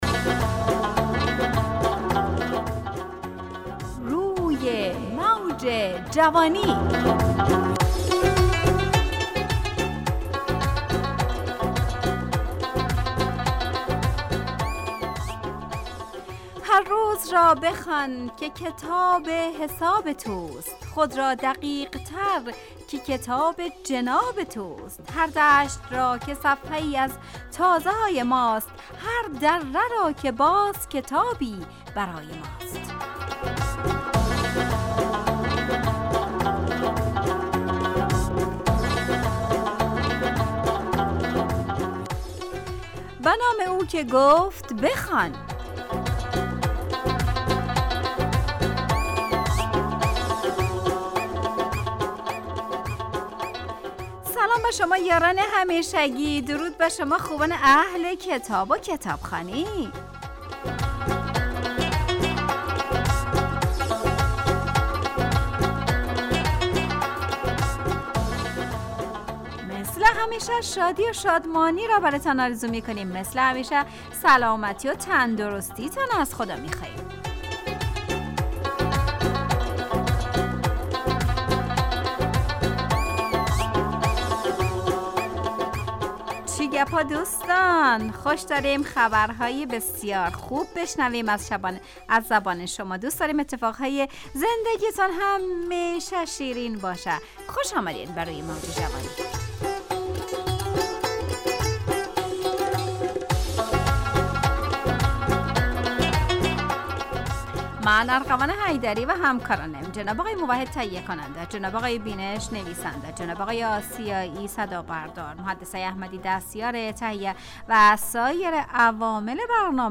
همراه با ترانه و موسیقی مدت برنامه 55 دقیقه . بحث محوری این هفته (اگر کتاب نخوانیم ... ) تهیه کننده